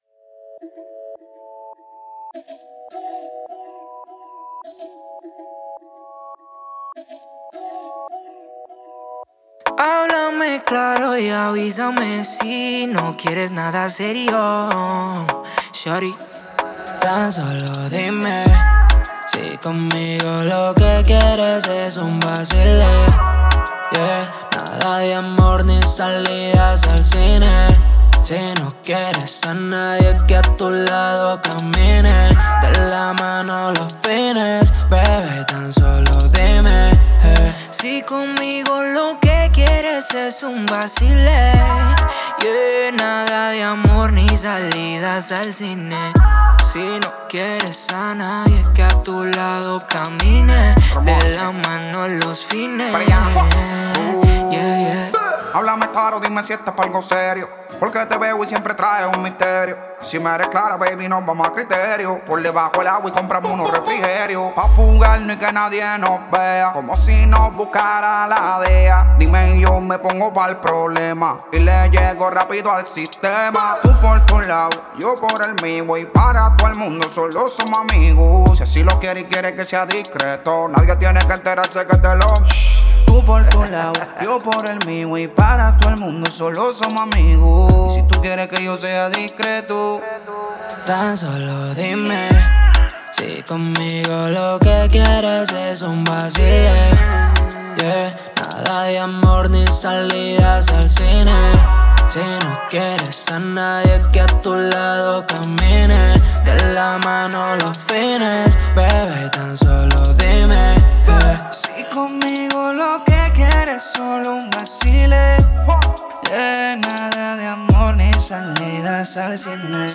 это яркая реггетон-композиция